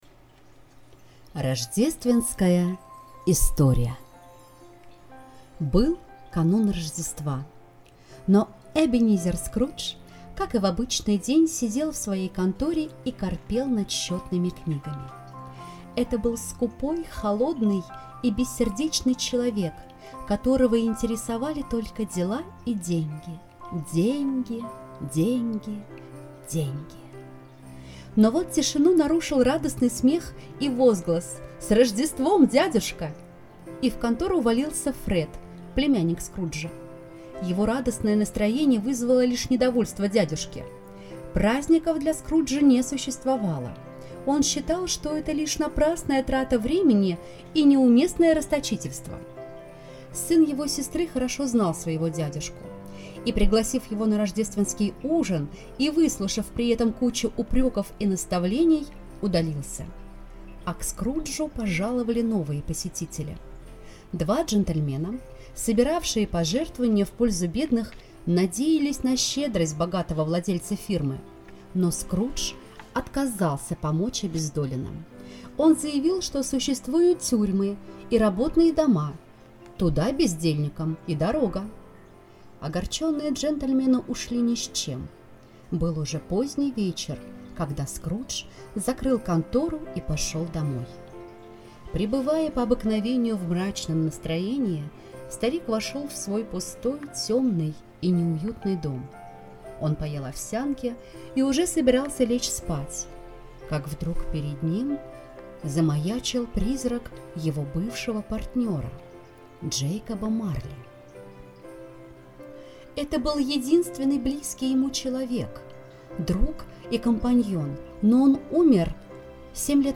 Сегодня мы предлагаем вам окунуться в мир чудес и волшебства, прослушивая “Рождественскую историю”, рассказанную по мотивам повести Чарльза Диккенса.